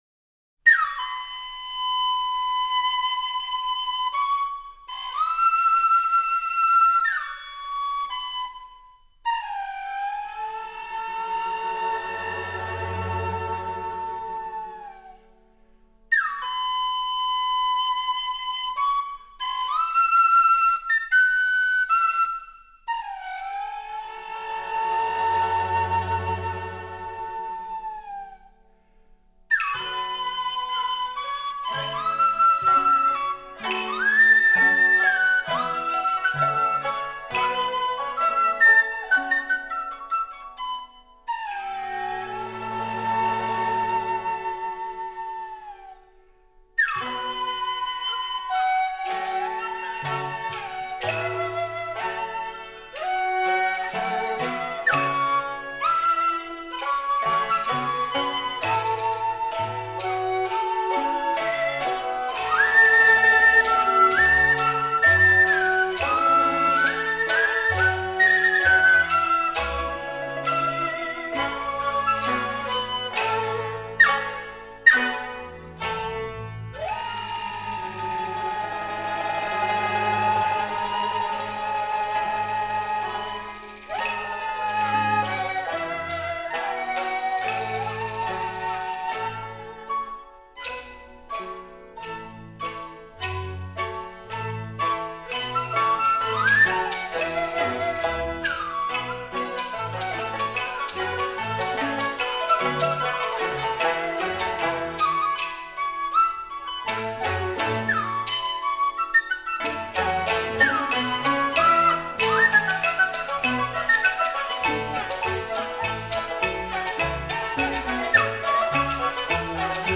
中国吹管乐
梆笛